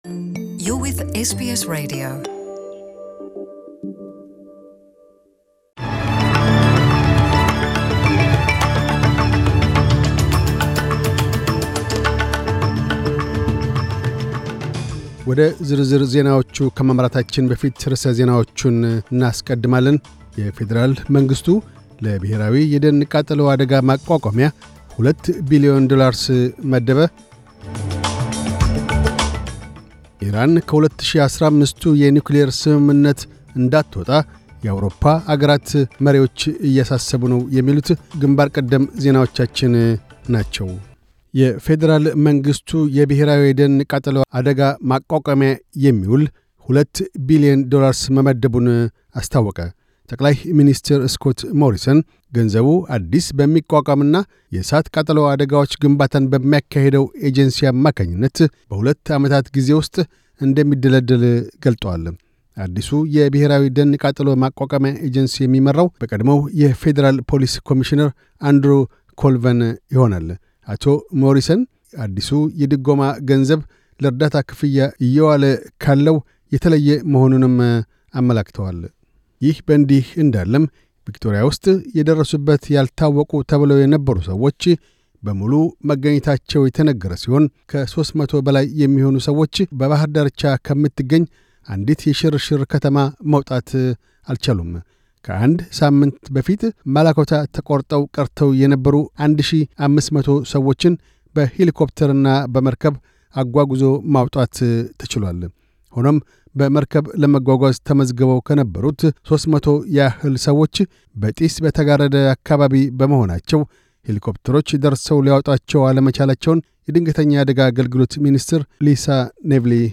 News Bulletin 0601